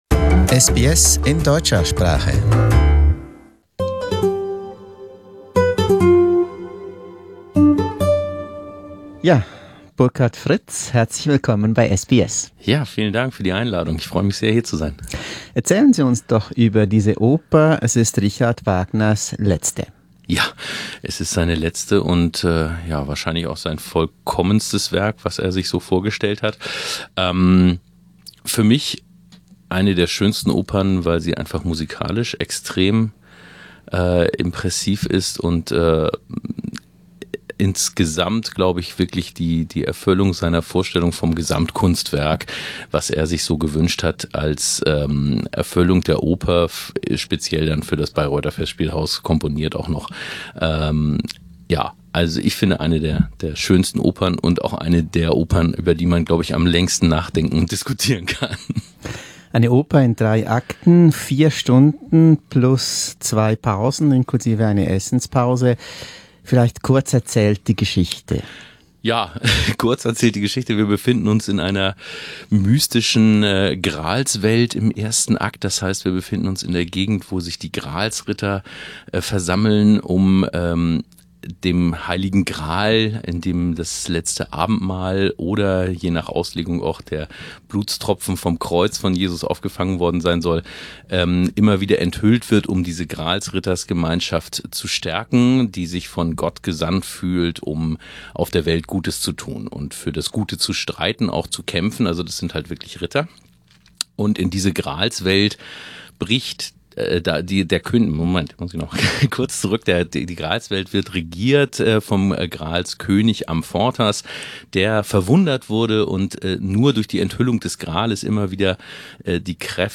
The beauty and magic of Wagner's last opera Parsifal: An interview with German tenor